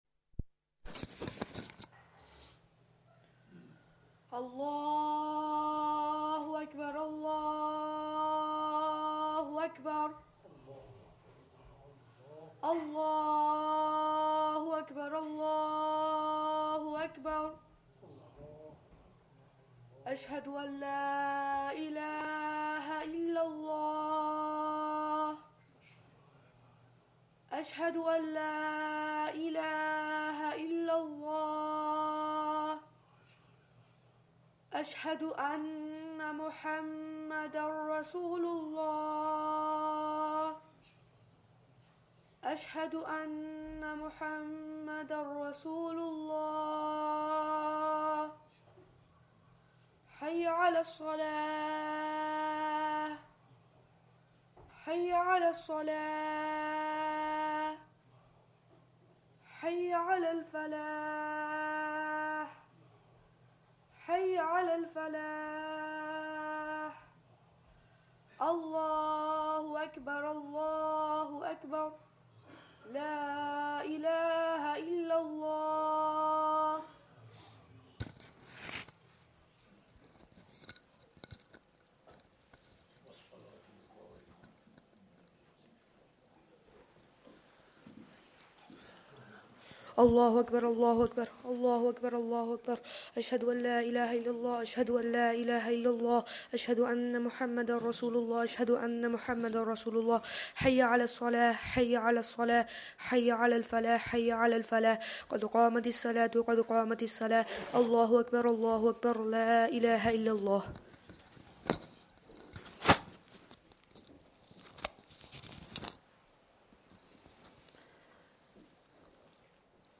eMasjid Live is an online streaming platform for Masaajids and Islamic radio stations. eMasjid Live has been built to provide an alternative to traditional analogue receivers whilst allowing users to listen to their local and UK wide Masaajids online wherever they are.
Madni Masjid, Langside Road, Glasgow